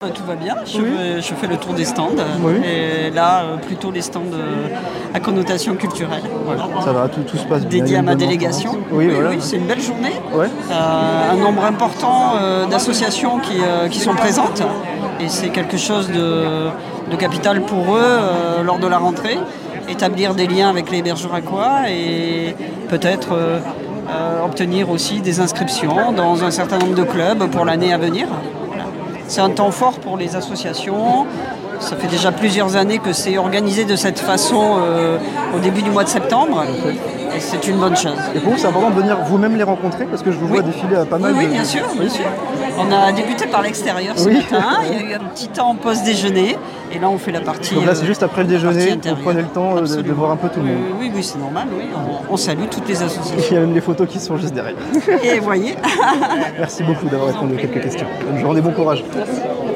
Week-end des associations 2025 Interview